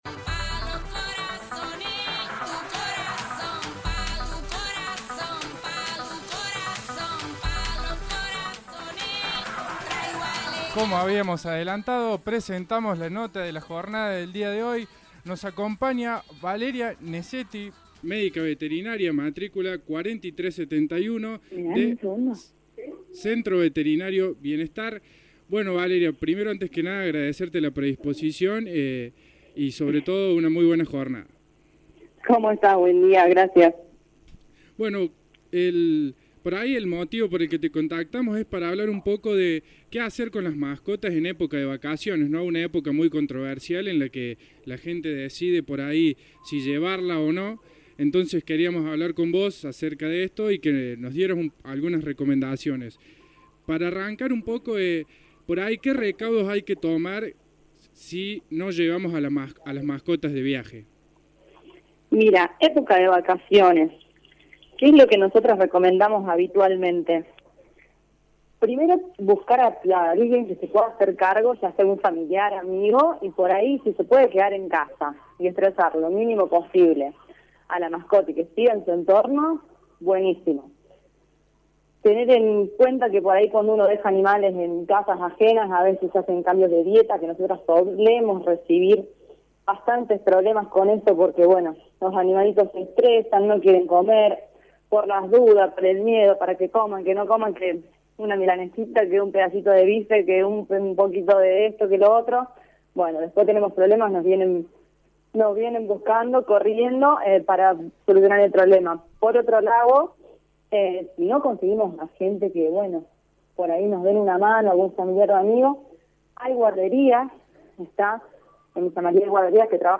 Escucha la nota completa: